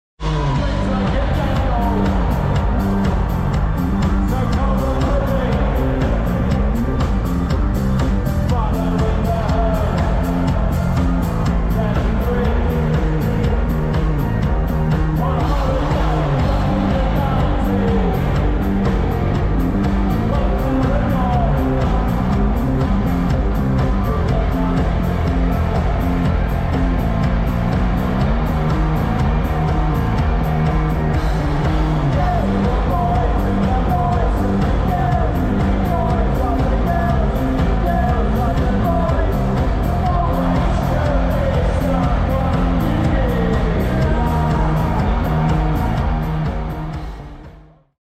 Special moments from Wembley